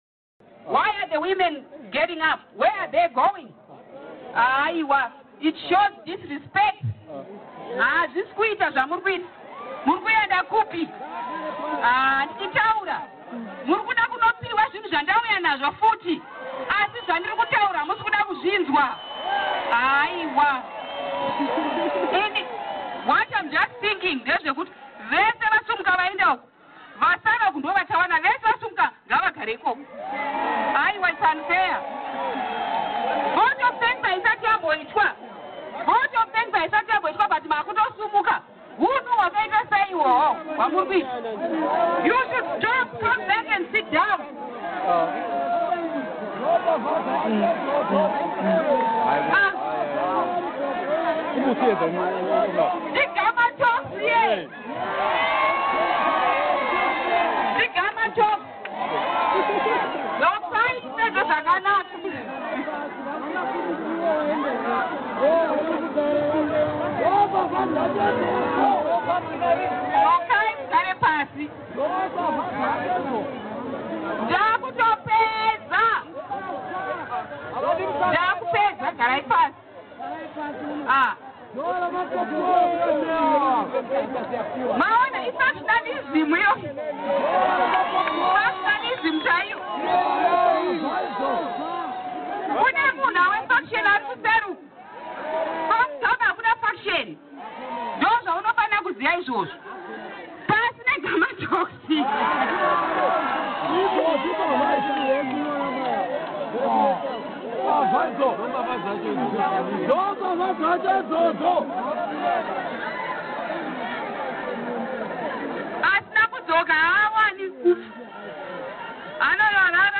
First Lady Grace Mugabe attacked some party members this week who walked out of a rally she was addressing in Zimbabwe's second largest city, Bulawayo. Zanu PF is crippled by serious infighting pitting Vice President Joice Mujuru and Justice Minister Emmerson Mnangagwa.